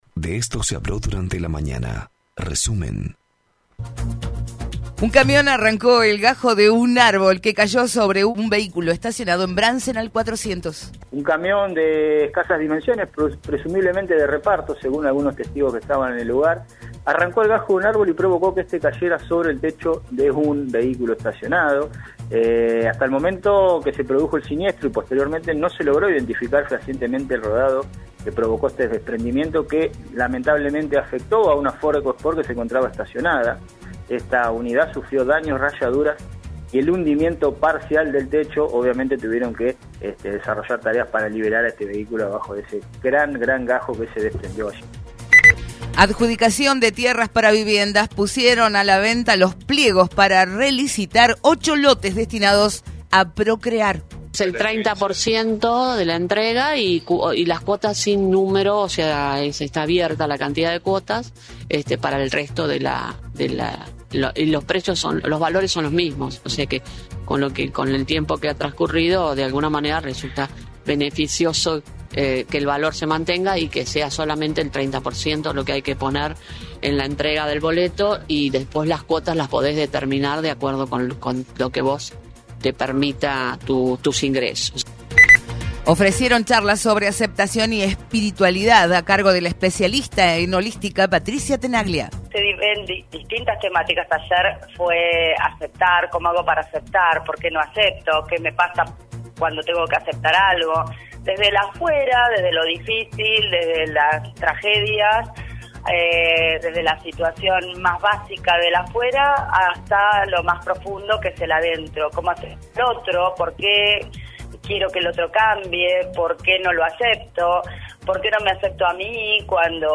Escuchá los principales temas noticiosos de la mañana en nuestro resumen de Radio 3 95.7.